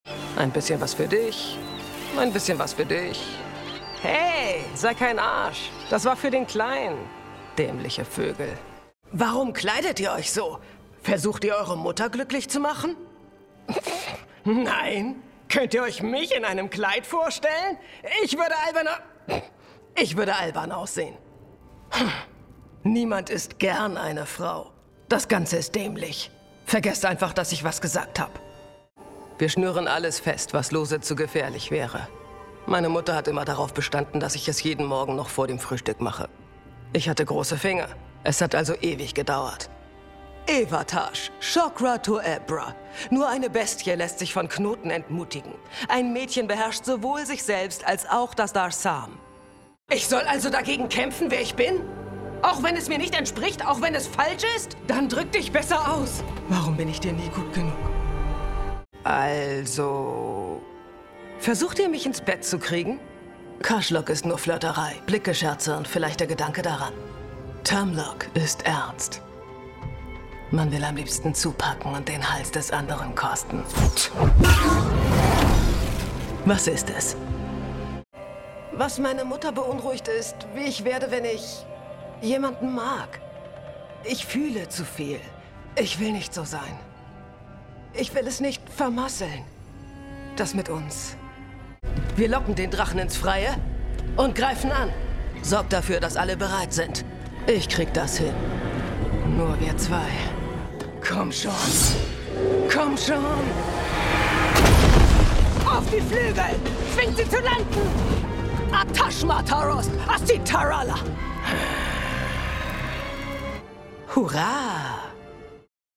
Taash (Qunari, Gefährtin)